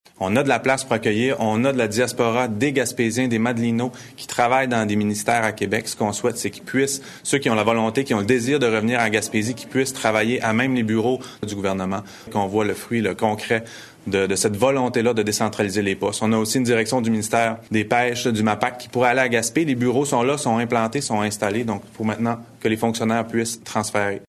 Le préfet de la MRC d’Avignon,Mathieu Lapointe, explique que le gouvernement doit s’impliquer pour redynamiser la Gaspésie et améliorer sa démographie. Il souligne notamment la promesse électorale de la CAQ de décentraliser 5000 emplois vers les régions: